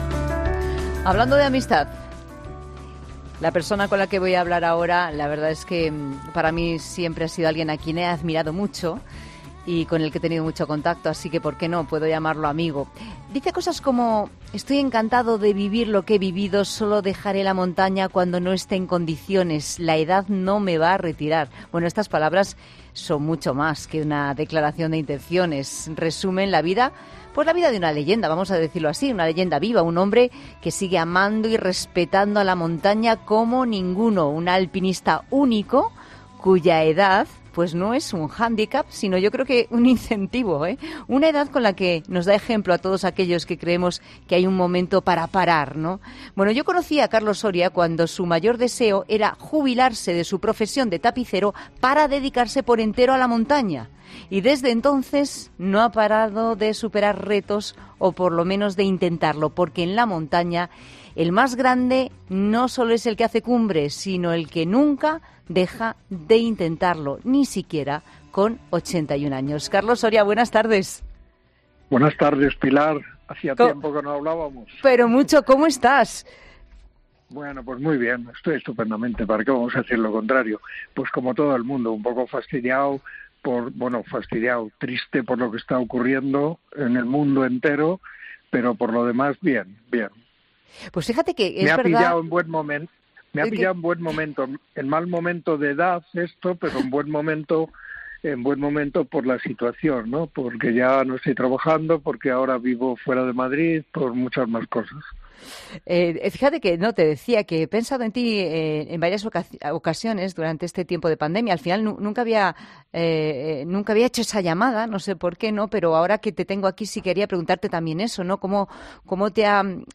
Hemos hablado con él en 'La Tarde', donde nos ha revelado que en estos momentos se encuentra "estupendamente".